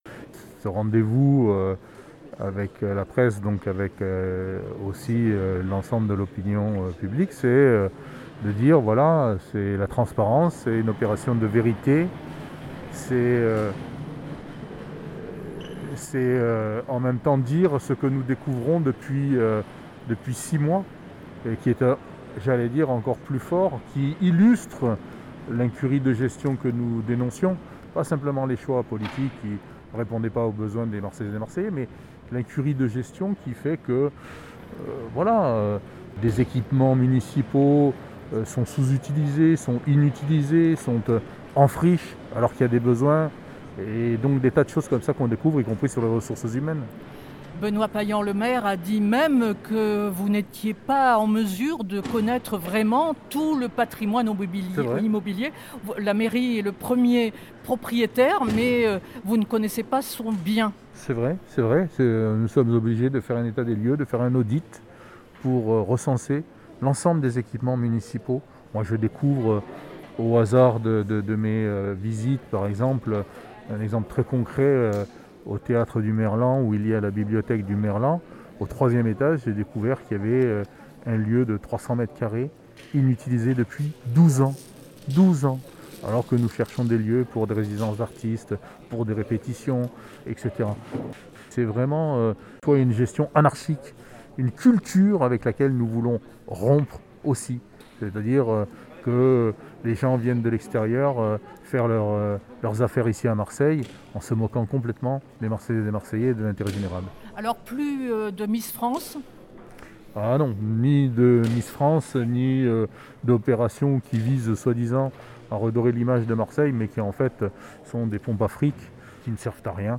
Entretien.
[(son_copie_petit-462.jpg Entretien avec Jean-Marc Coppola, adjoint en charge de la Culture de la ville de Marseille